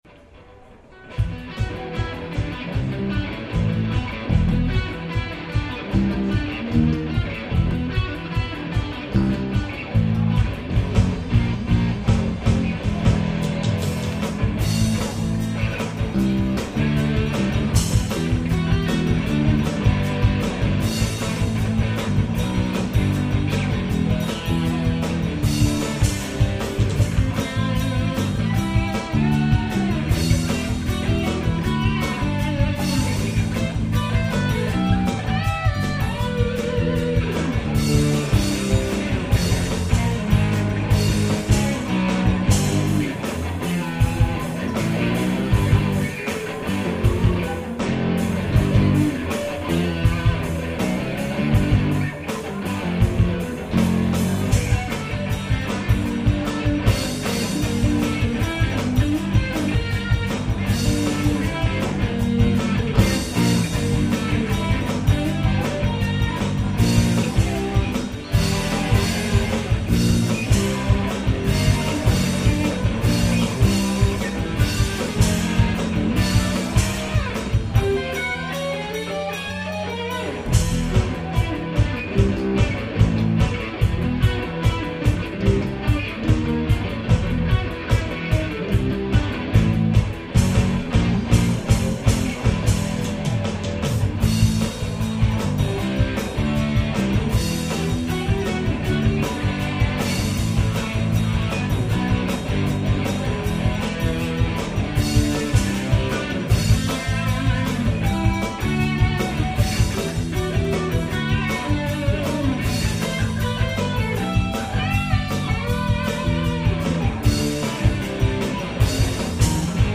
"Live" at the Metaphor Cafe in Escondido, CA
Bass
Electronic Drums